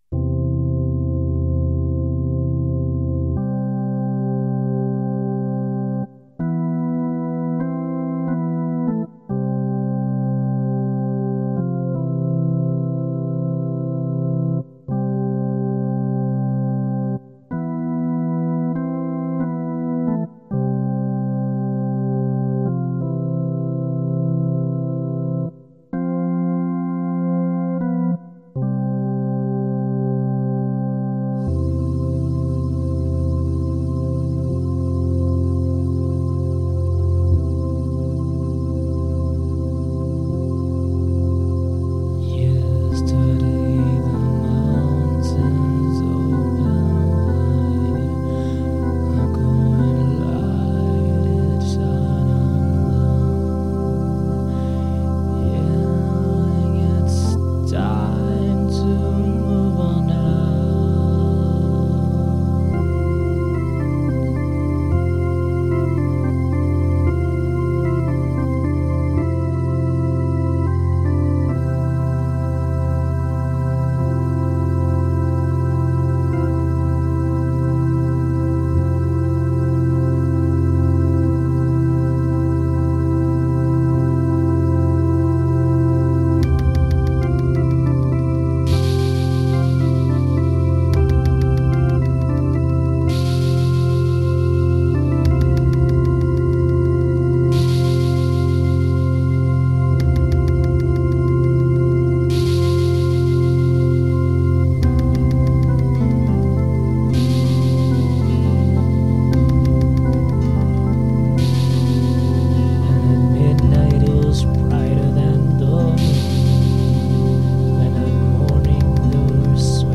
volcano2.mp3